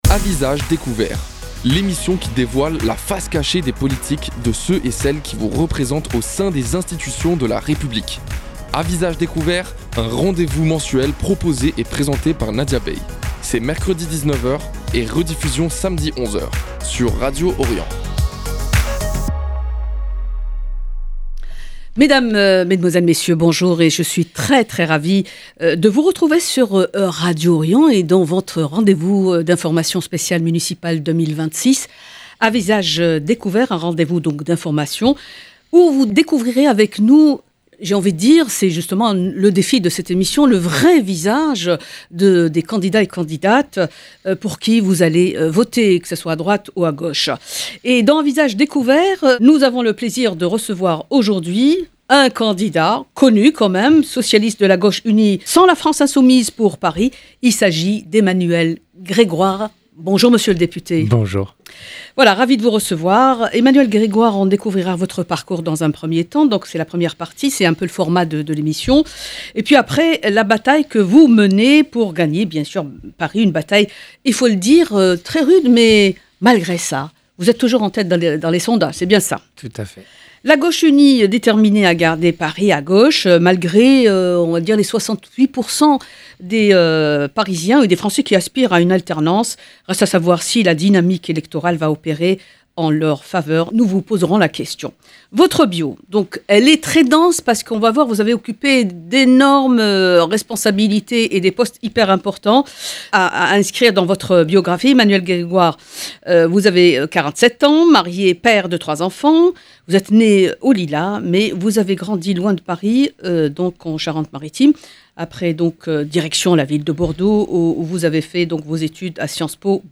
Invité de « À Visage Découvert », Emmanuel Grégoire , candidat de l’union de la gauche à la mairie de Paris, présente les grandes lignes de son projet pour la capitale. Il évoque un programme ambitieux visant à répondre aux préoccupations quotidiennes des Parisiennes et des Parisiens, avec l’objectif de rapprocher davantage l’action municipale des habitants et de préparer la bataille des municipales de 2026. 0:00 34 min 4 sec